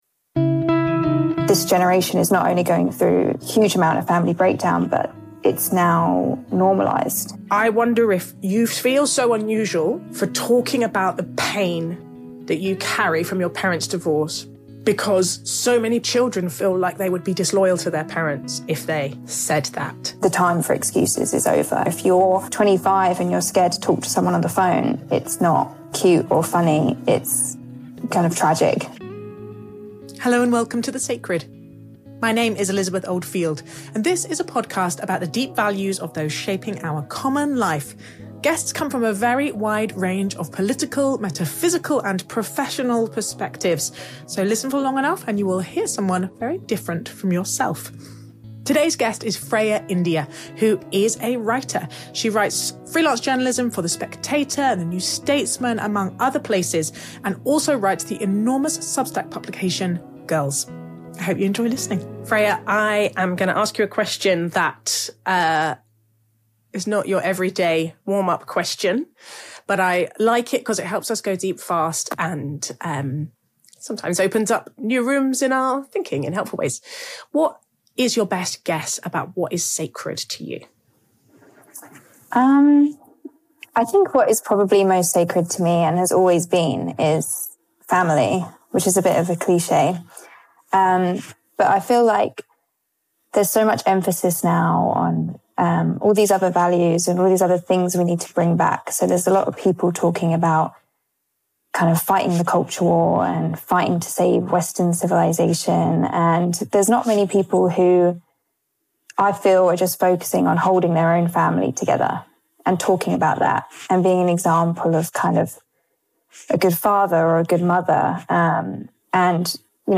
The Sacred is a podcast about our deepest values, the stories that shape us and how we can build empathy and understanding between people who are very different. Each episode features a conversation with someone who has a public voice, from academics to journalists, playwrights and politicians.